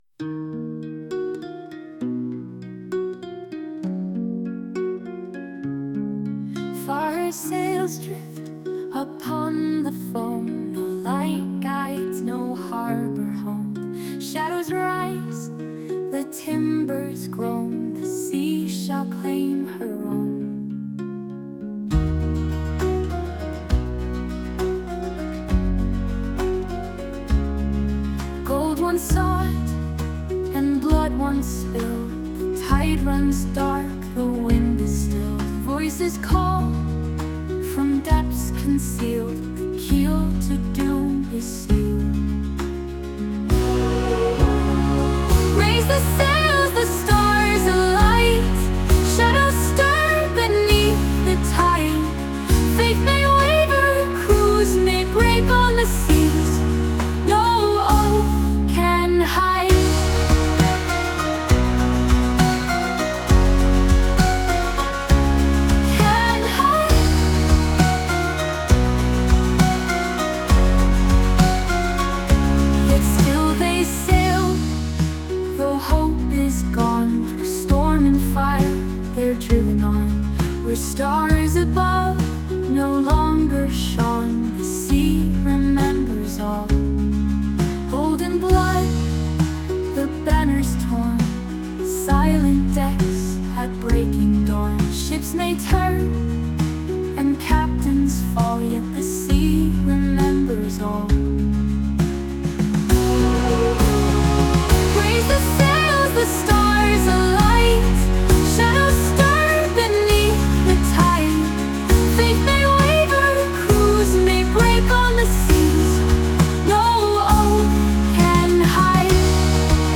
There’s a theme song for the game.
I just gave ChatGPT some direction on what sort of lyrics I was looking for with some example songs and the mood, iterating a few times for the right verses and choruses, then fed that into the Suno music generator with some more direction on Celtic, ethereal folk music and whatnot.